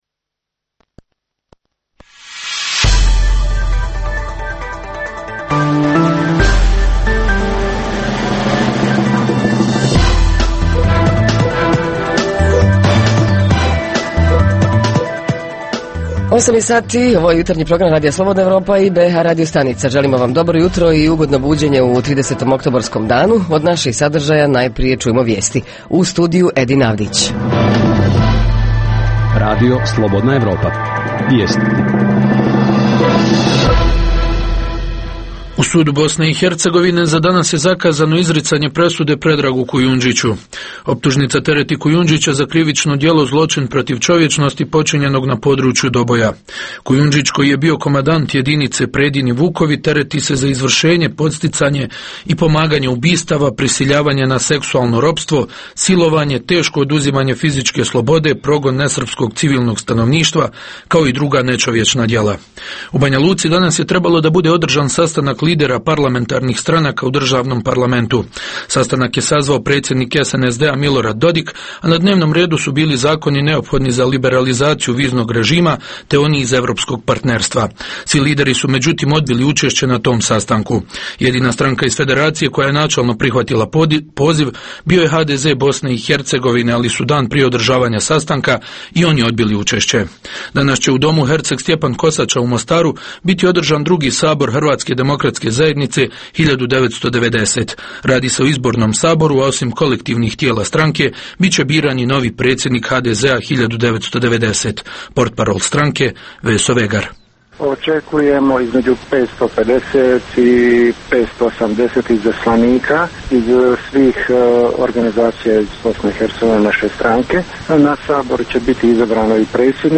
Zajednička tema Jutarnjeg programa: rad gradskih sportskih klubova Reporteri iz cijele BiH javljaju o najaktuelnijim događajima u njihovim sredinama.
Redovni sadržaji jutarnjeg programa za BiH su i vijesti i muzika.